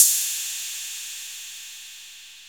808-Ride4.wav